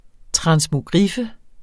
Udtale [ tʁɑnsmoˈgʁifə ]